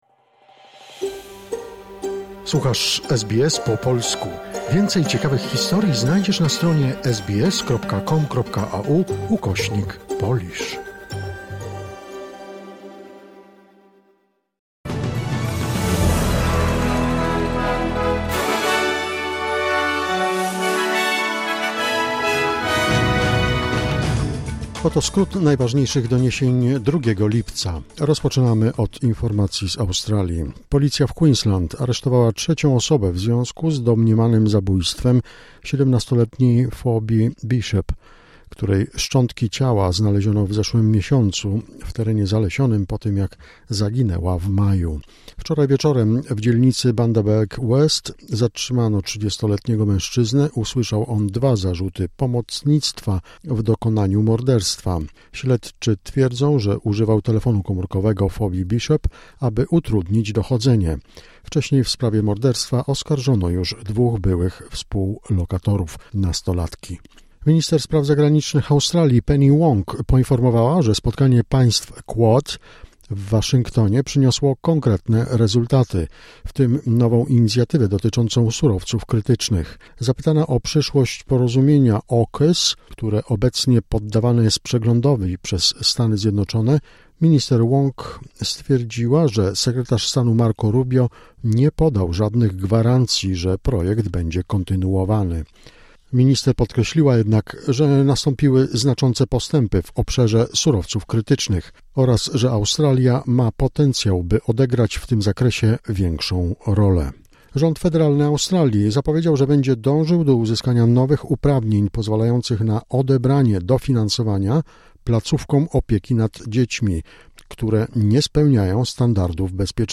Wiadomości 2 lipca SBS News Flash